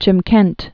(chĭm-kĕnt)